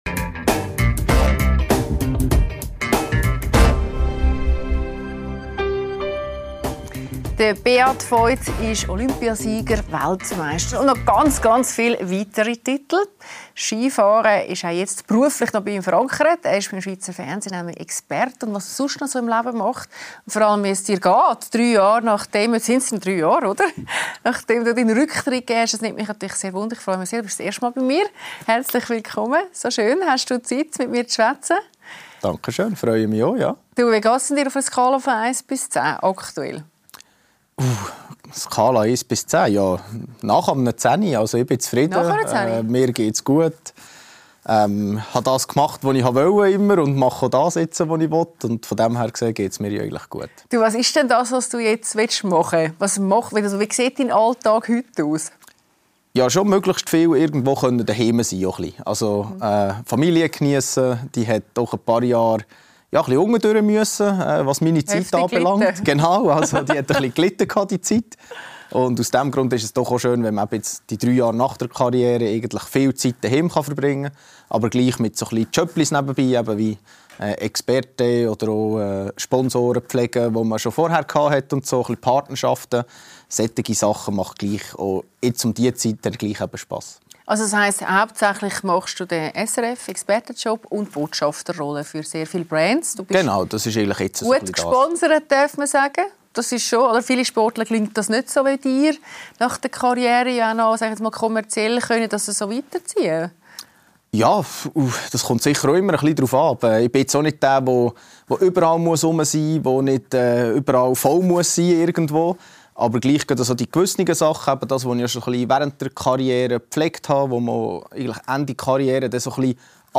Beat Feuz erzählt von prägenden Kindheitsmomenten, Erfolgen und Tiefschlägen auf der Piste und seinem Alltag als SRF-Ski-Experte. Warum Skifahren für ihn mehr bleibt als nur ein Job und was ihm als Vater wichtig ist – im Gespräch mit Claudia Lässer.